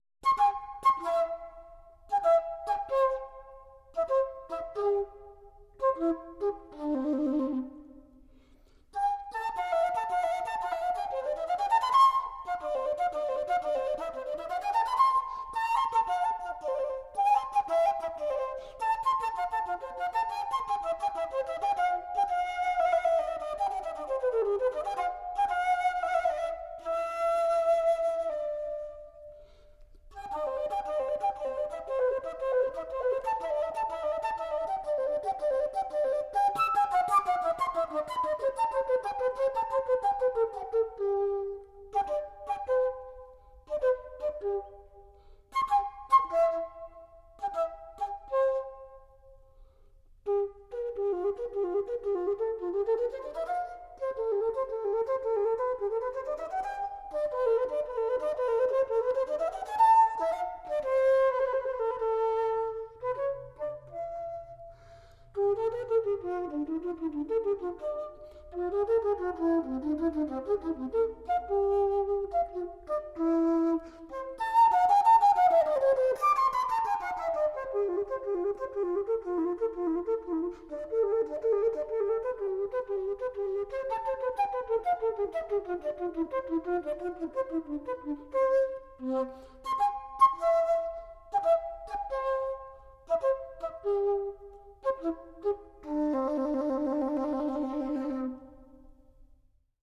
Querflöte mit zugehörigem Kasten von Johann Joachim Quantz
in D-Dur